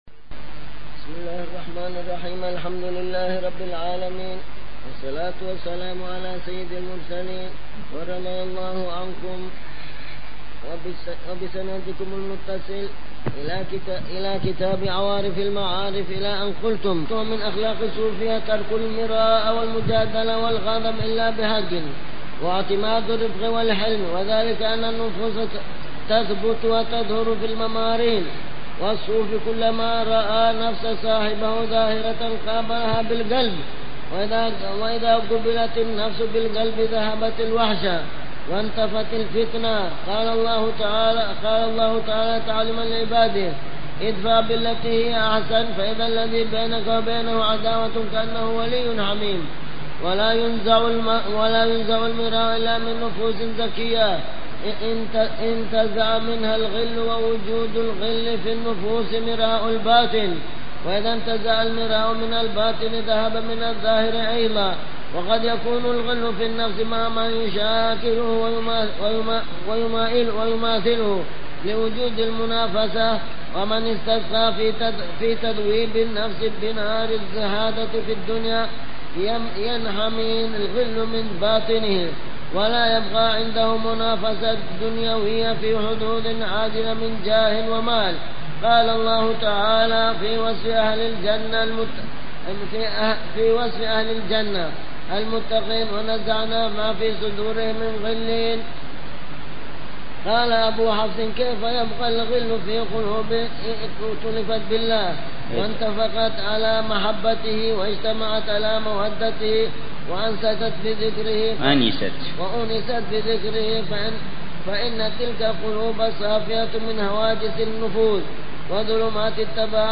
شرح كتاب عوارف المعارف - الدرس الثالث والأربعون - ومن أخلاقهم: ترك المراء والمجادلة والغضب إلا بحق
شرح لكتاب عوارف المعارف للإمام السهروردي ضمن دروس الدورة التعليمية الثانية عشرة والثالثة عشرة بدار المصطفى في صيف عامي 1427هـ و 1428